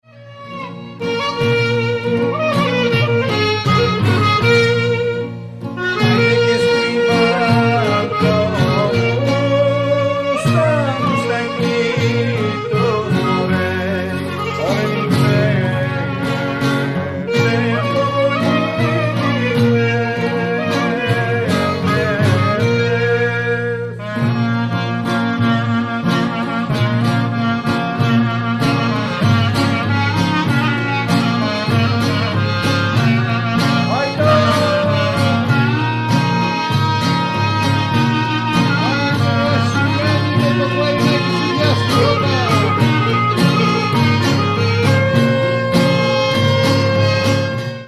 The meter is also a variant of the Tsamikos.